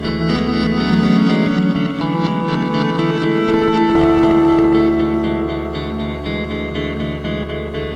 Fourteen notes in just under eight seconds. It's recorded to loop seamlessly when repeated, so it can run as long as the equipment (or listener) holds out.
Drawing I (0:08), basic loop.